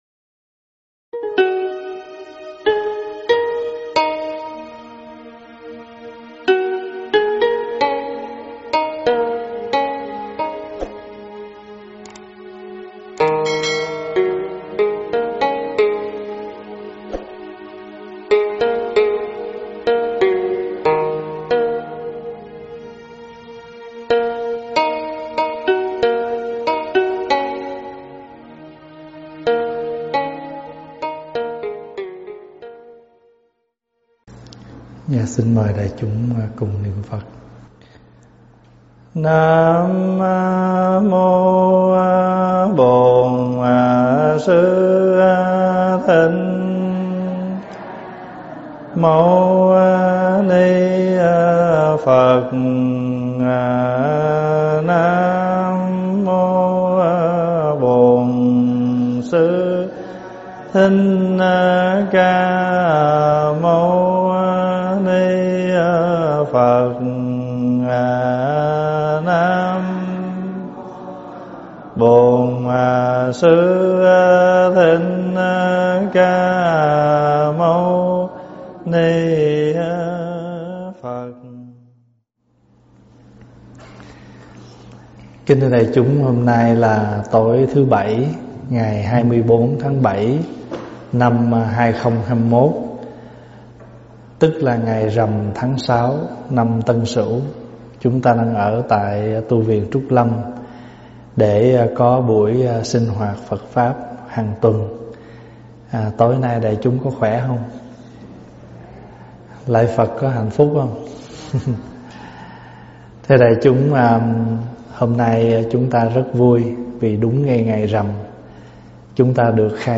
thuyết pháp Trách Nhiệm Phật Trao 11